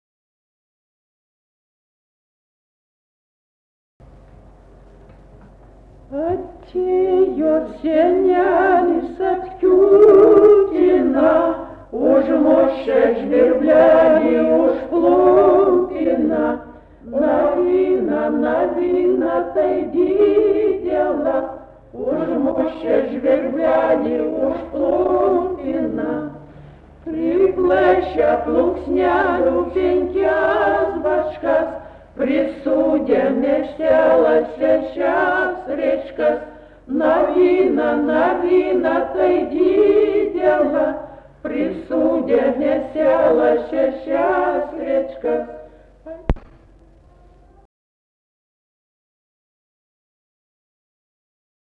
smulkieji žanrai
Atlikimo pubūdis vokalinis